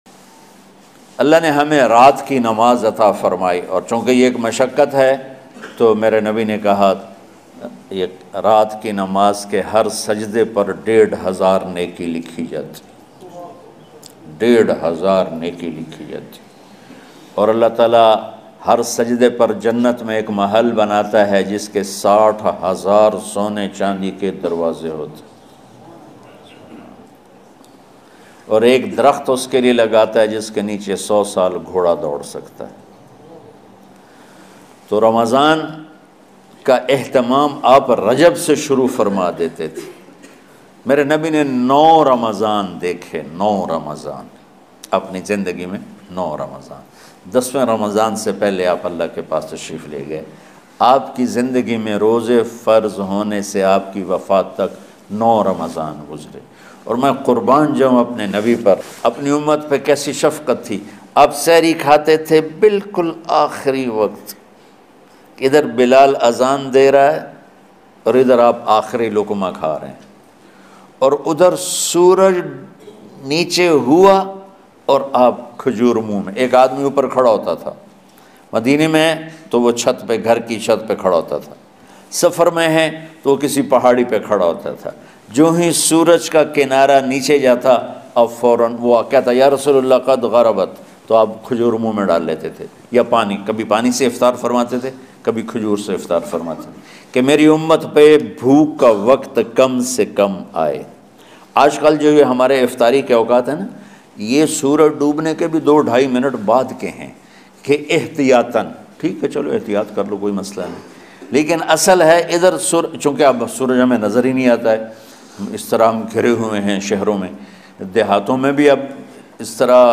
Maulana Tariq Jameel lattest bayan mp3 free download.
new-urdu-bayan-2019 .mp3